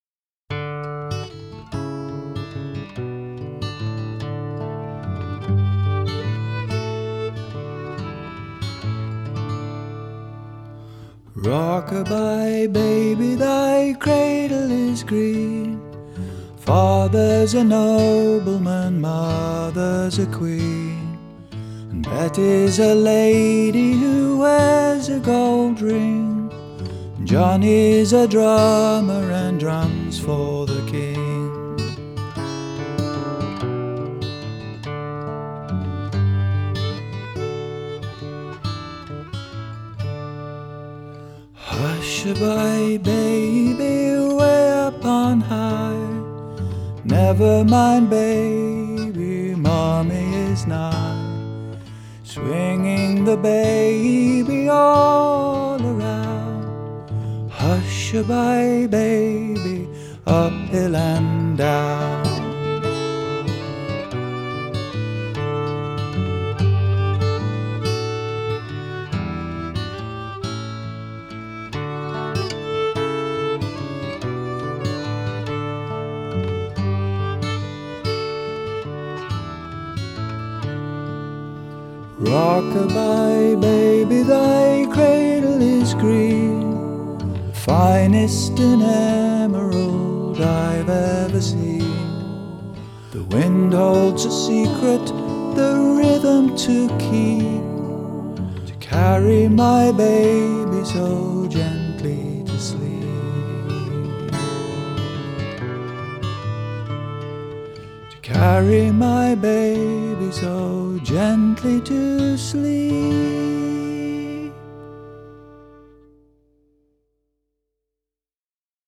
LullabyFolk